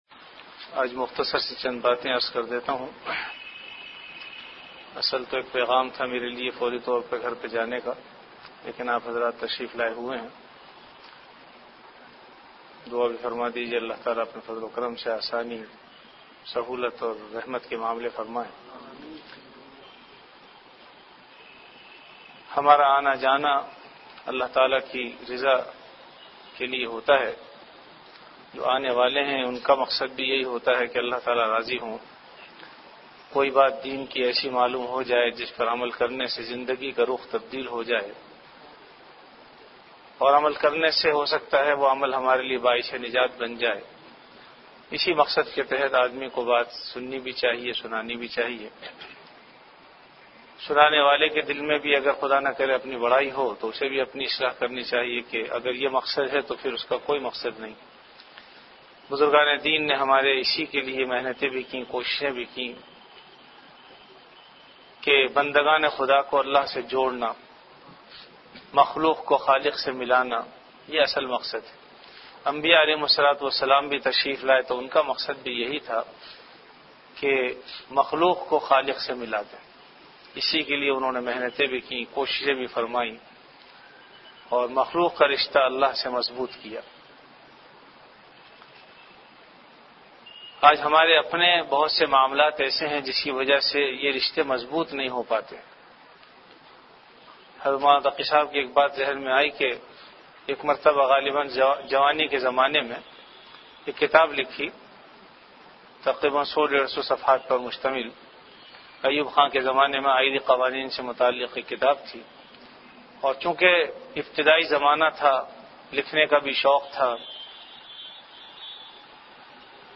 CategoryBayanat
VenueJamia Masjid Bait-ul-Mukkaram, Karachi
Event / TimeAfter Isha Prayer